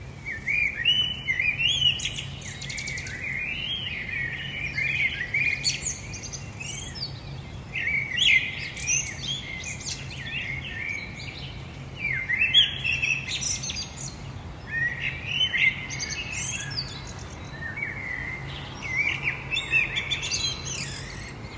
Birds singing in the morning in the Stockholm suburbs 17 May 2024 at 03:17 We sometimes have a whole concerto going off, (sometimes even in middle of the night at times). This is what it sounded like at my balcony, was the light started to get orange-red, while listening to the birds.